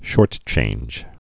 (shôrtchānj)